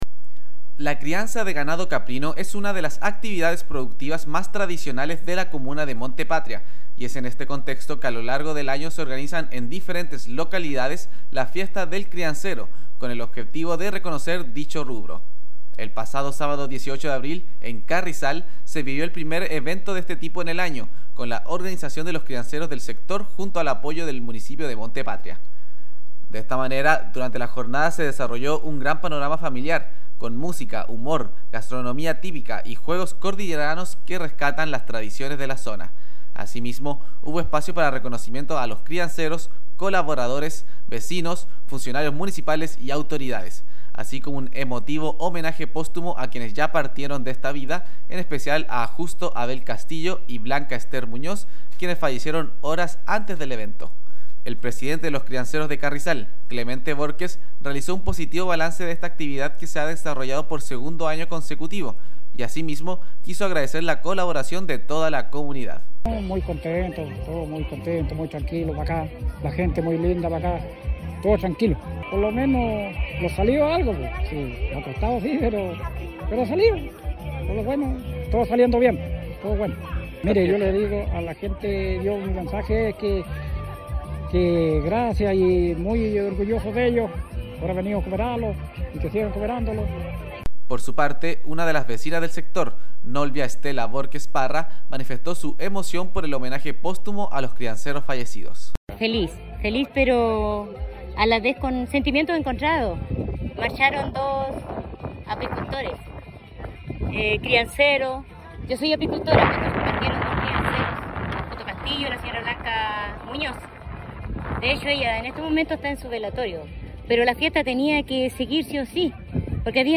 DESPACHO-FIESTA-DEL-CRIANCERO-DE-CARRIZAL.mp3